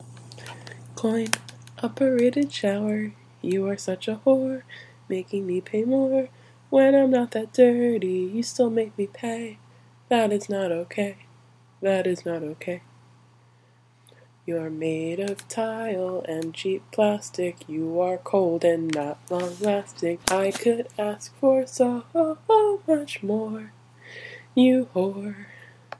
coin operated shower